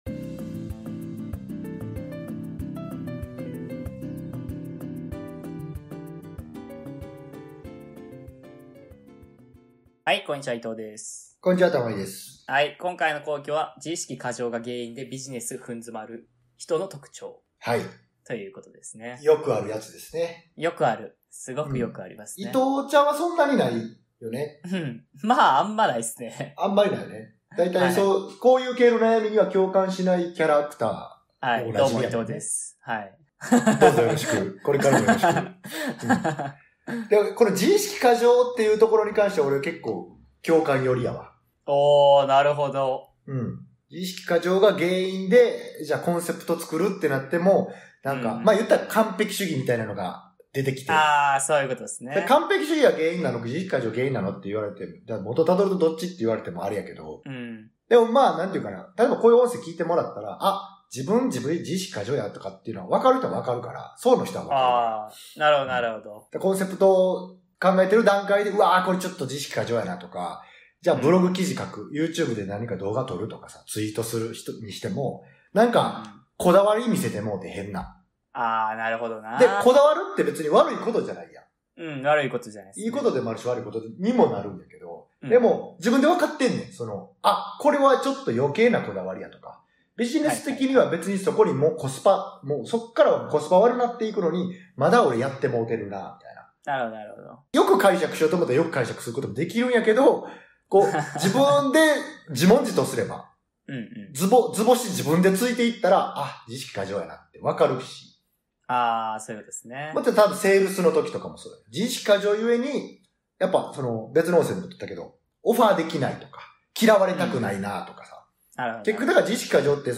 2人で共同運営するビジネススクール「BizCamp」のコンテンツの一部です。